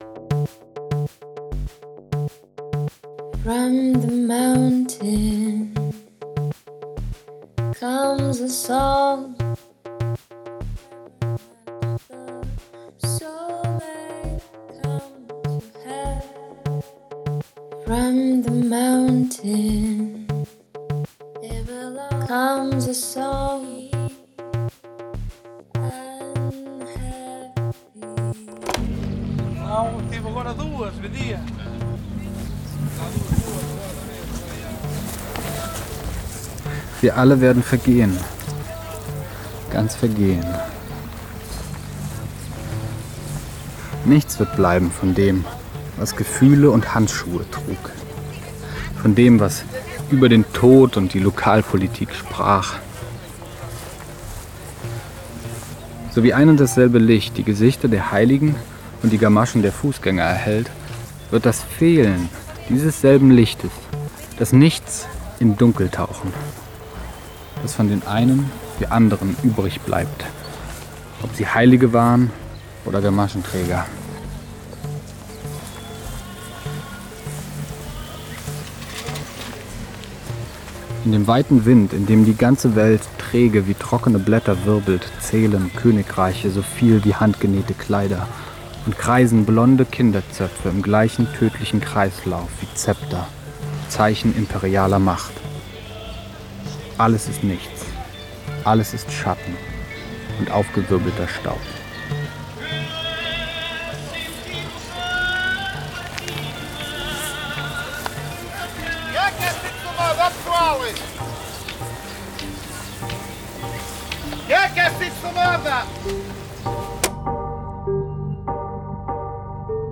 Radio | Hörspiel
Saudade, jene typisch portugiesische Form des Weltschmerzes, ist hier elektronisch verfremdeter Klang geworden.“